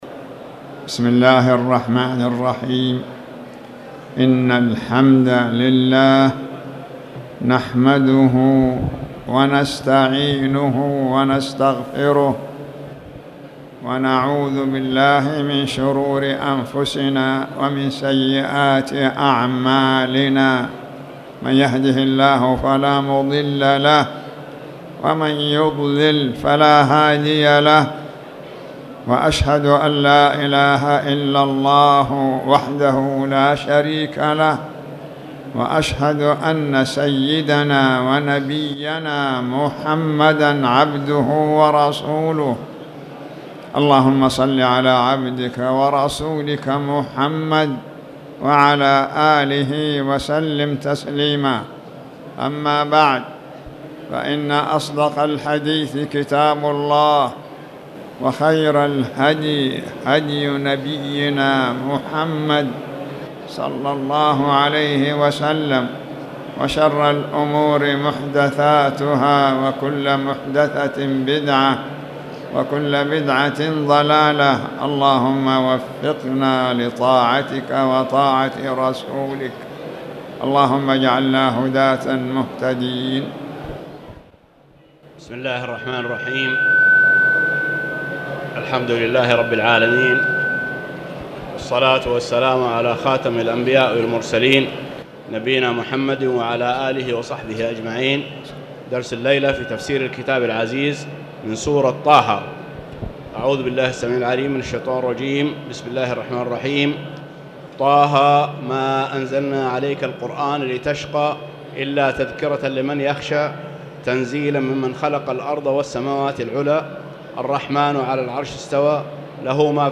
تاريخ النشر ١٨ جمادى الأولى ١٤٣٨ هـ المكان: المسجد الحرام الشيخ